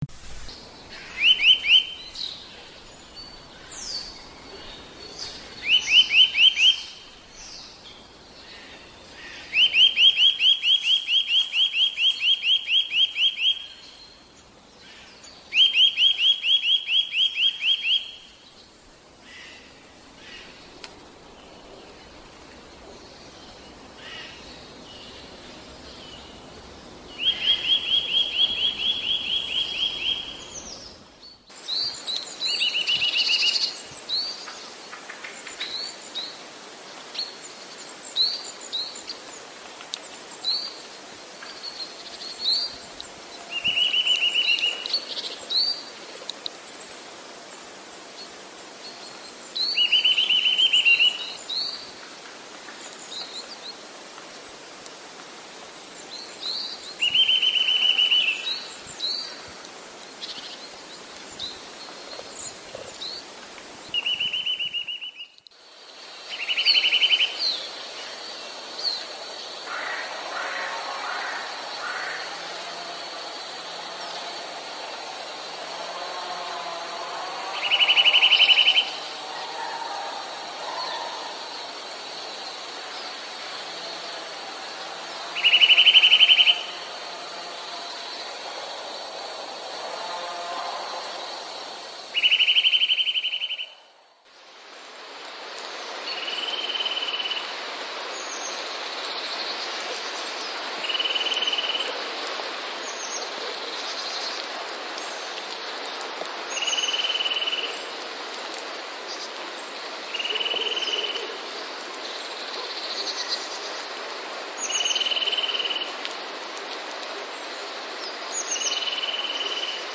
Kowalik - Sitta europaeus
Kowaliki potrafią też wykonywać
tryle w wolnym lub szybkim tempie.
kowalik_tryle.mp3